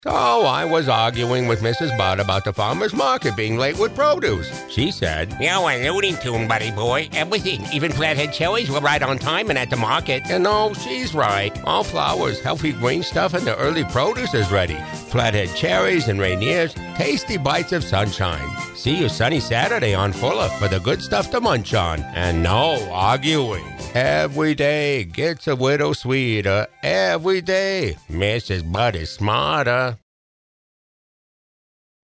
Also, Farmer Bud began with an expected element, the eheheheh chuckle.
Farmer Bud began relating stories about Bud Junior or Mrs. Bud (mimicking their voices as well).